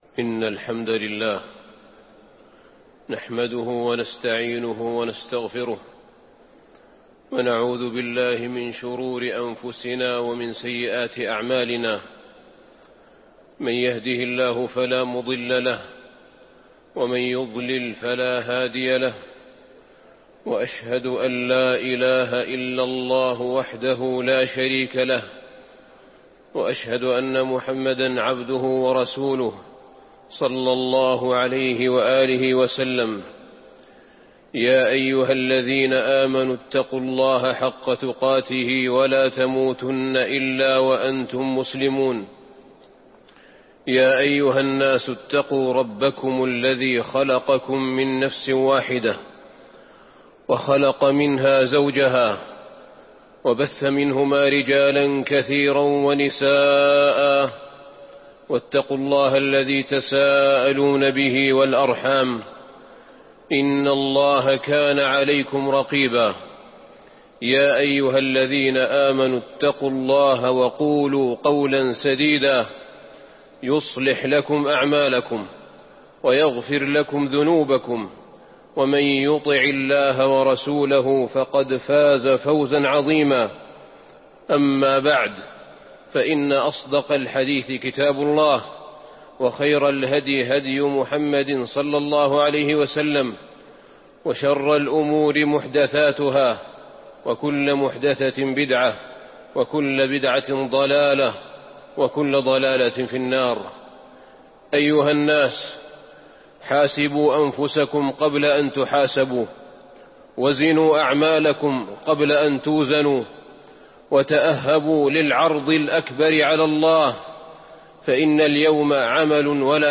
تاريخ النشر ٢٤ جمادى الأولى ١٤٤٢ هـ المكان: المسجد النبوي الشيخ: فضيلة الشيخ أحمد بن طالب بن حميد فضيلة الشيخ أحمد بن طالب بن حميد لينذر يوم التناد